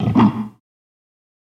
Gorilla Grunt